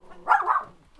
barking_dog_172KB.wav